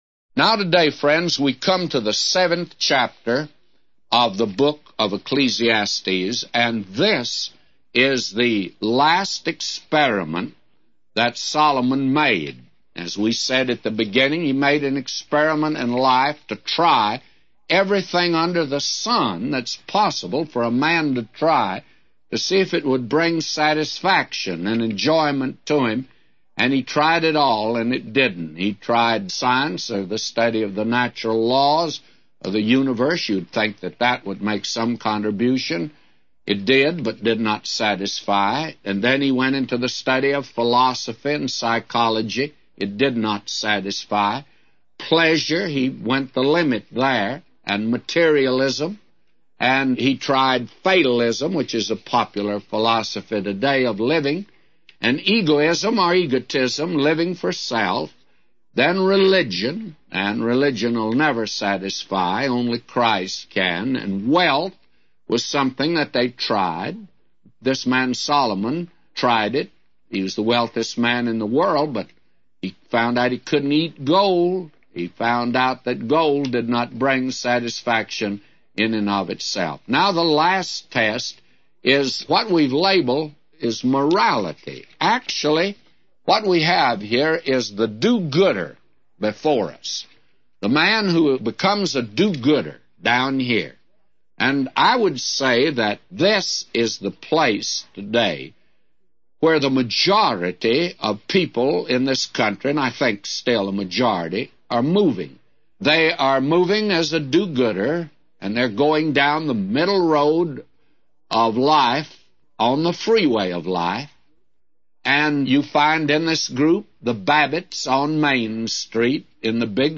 A Commentary By J Vernon MCgee For Ecclesiastes 7:1-999